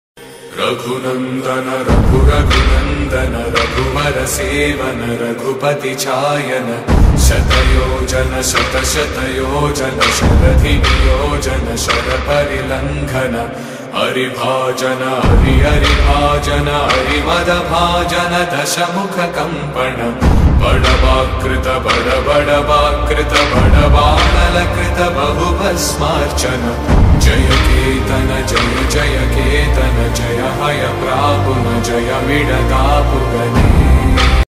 clear devotional sound